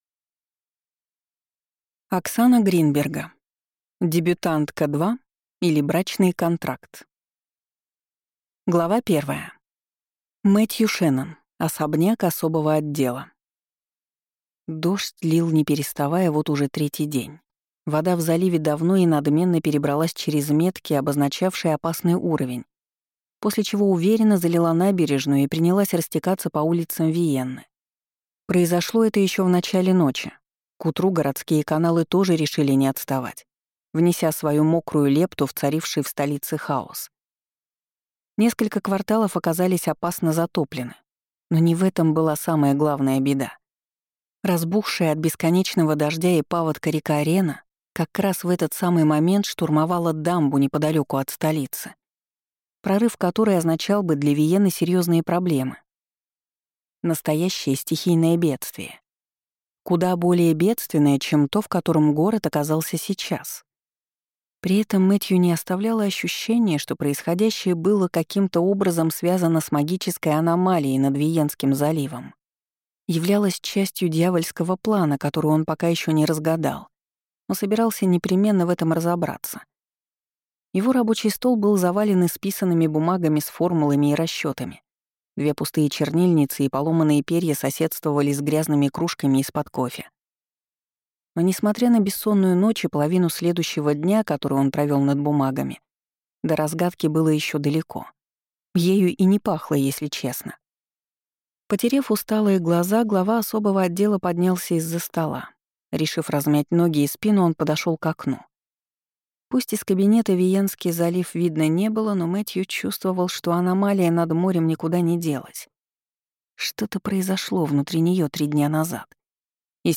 Аудиокнига Дебютантка – 2, или Брачный Контракт | Библиотека аудиокниг